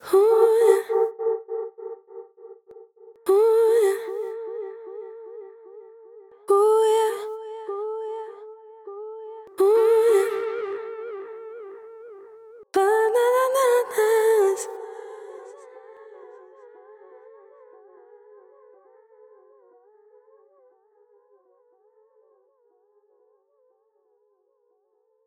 Grundsätzlich ist neben dem fast schon zu sauberen Klang die einfache Bedienung des Plugins ein positiver Aspekt. Der Schimmer des Federhalls ist dafür sehr authentisch nachgebildet und die zusätzlichen Funktionen wie Flatter- und Bandgeräuschkontrolle sorgen für den nötigen analogen Schmutz.
arturia_tape_201_delay.mp3